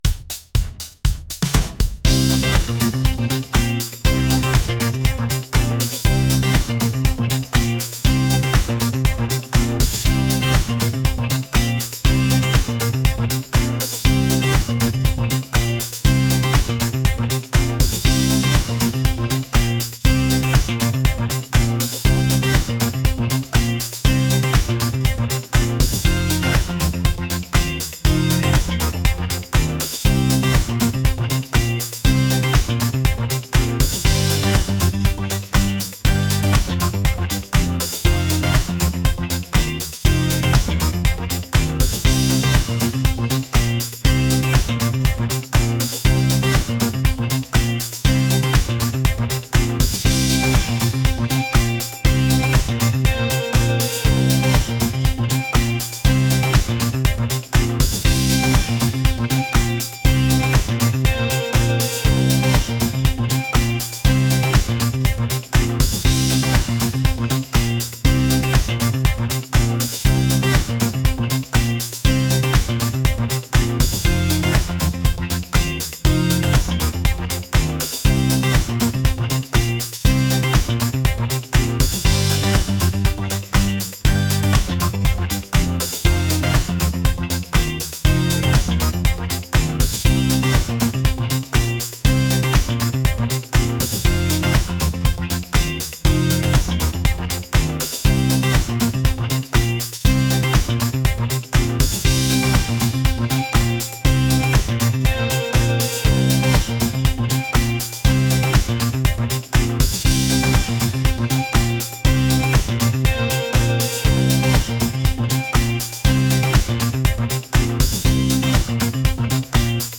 energetic | pop